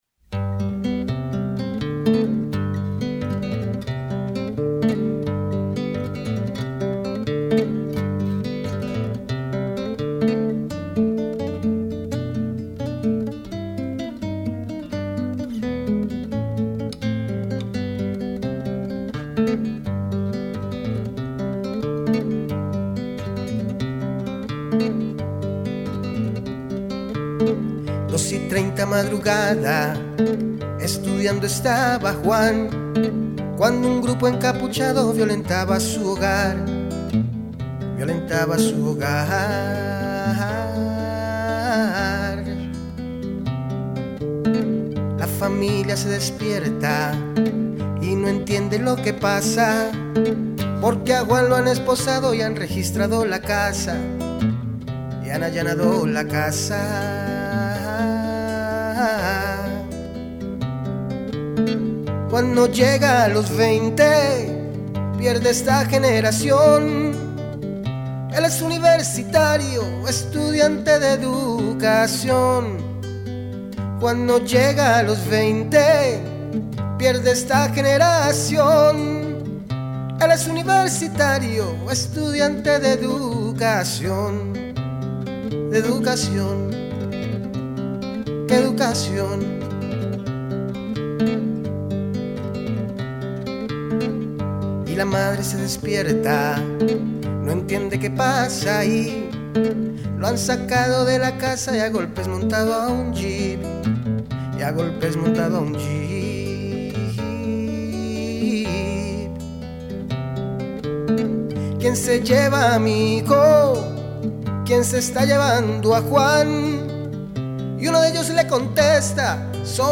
voz y guitarra.